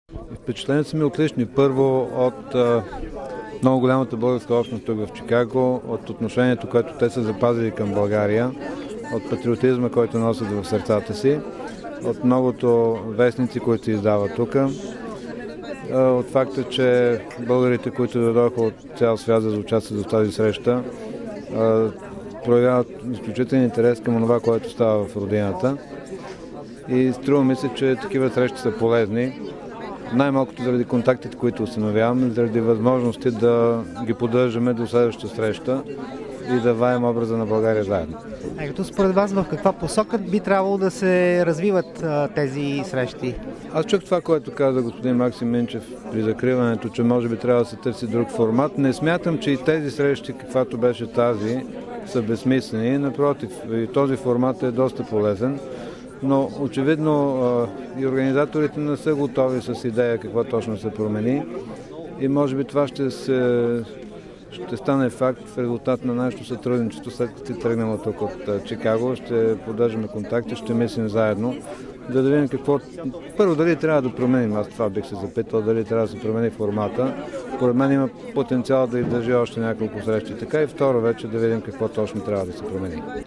Иво Атанасов, председател на медийната комисия към Парламента: RealAudio